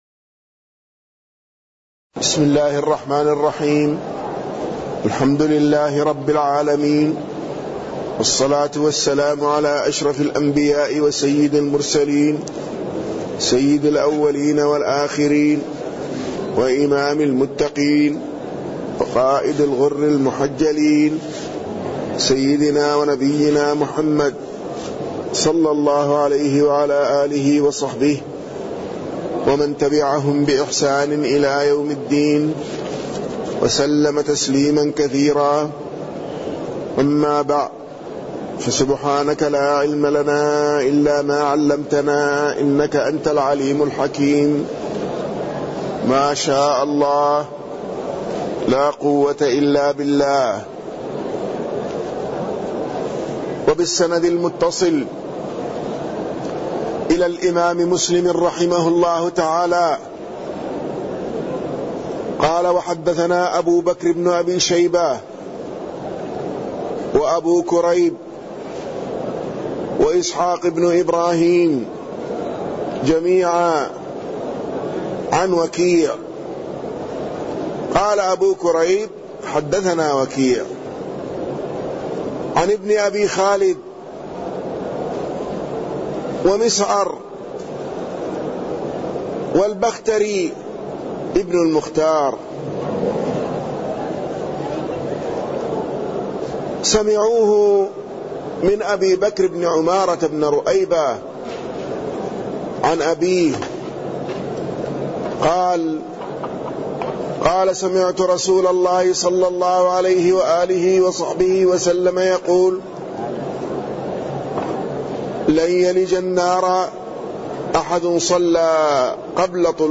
تاريخ النشر ١٥ محرم ١٤٣٠ هـ المكان: المسجد النبوي الشيخ